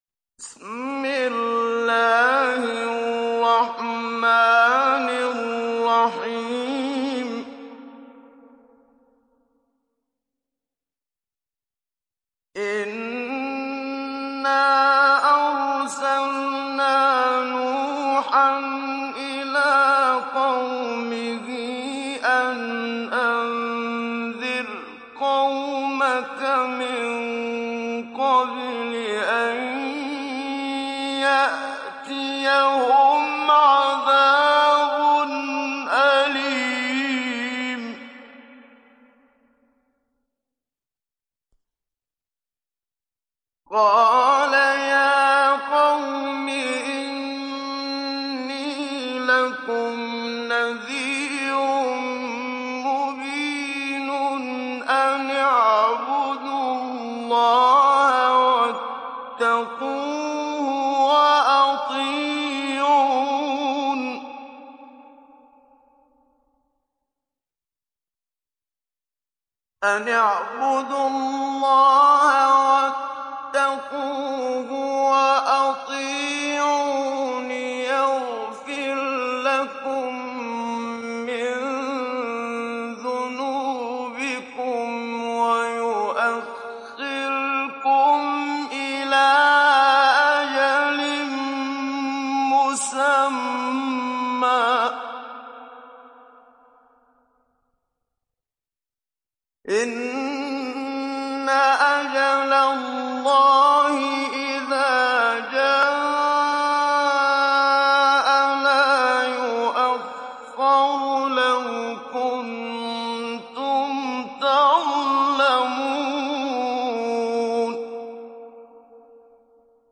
ডাউনলোড সূরা নূহ Muhammad Siddiq Minshawi Mujawwad